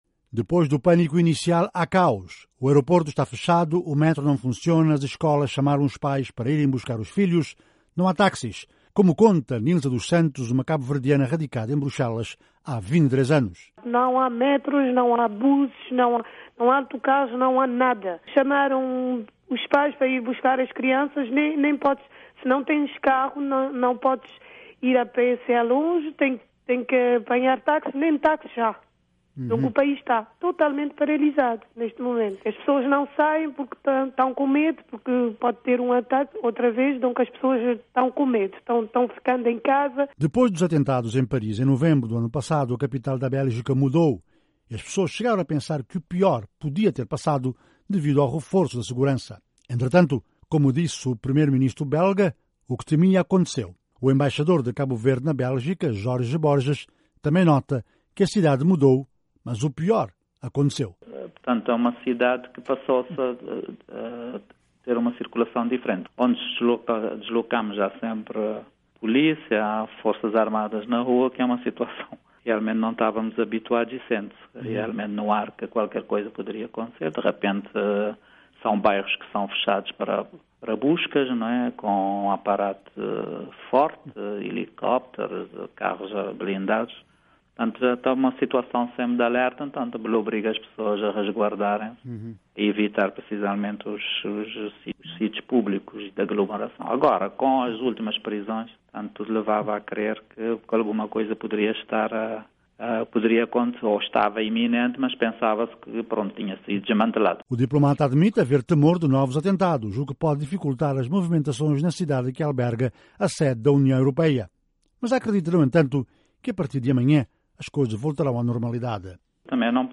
"Depois do pânico, o caos em Bruxelas", relata embaixador cabo-verdiano na Bélgica
Jorge Borges, embaixador de Cabo Verde em Bruxelas